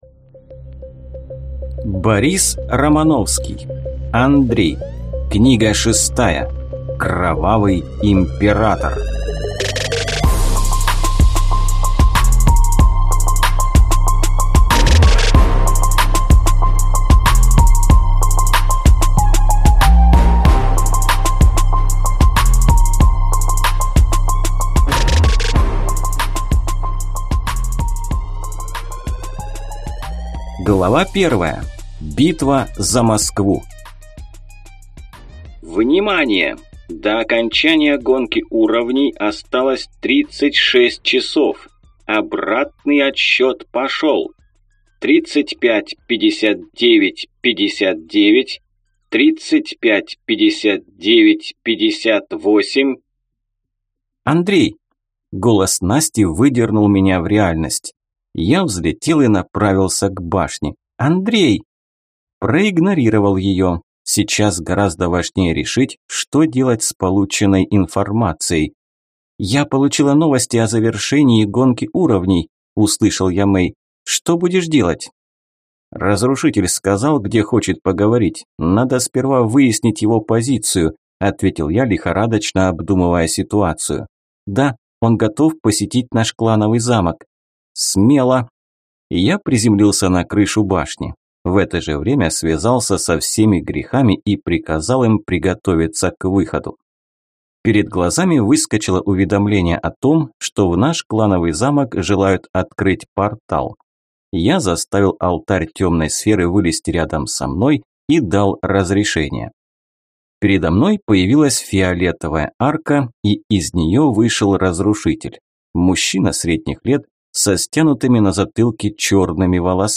Аудиокнига Кровавый Император | Библиотека аудиокниг